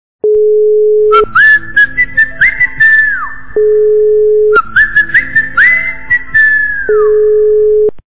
» Звуки » Люди фразы » Зазывающий - мелодичный свист
При прослушивании Зазывающий - мелодичный свист качество понижено и присутствуют гудки.
Звук Зазывающий - мелодичный свист